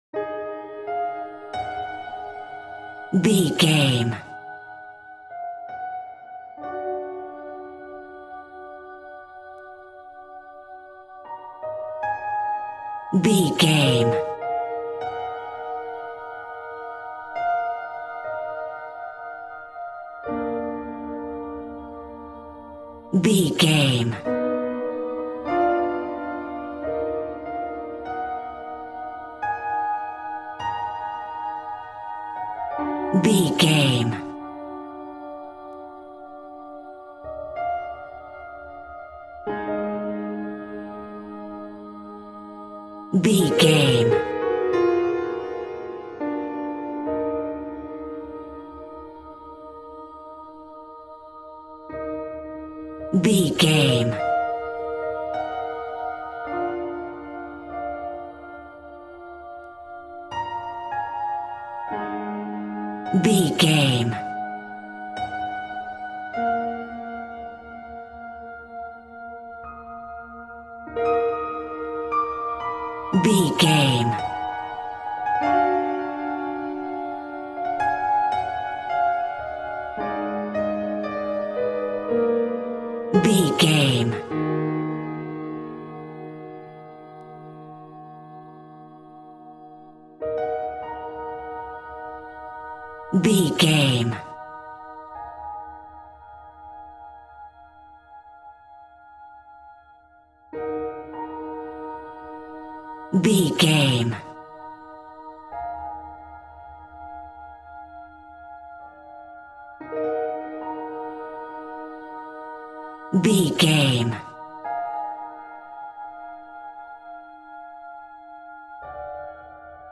Music That Is Scary.
Aeolian/Minor
tension
ominous
dark
suspense
haunting
eerie
strings
synthesiser
ambience
pads
eletronic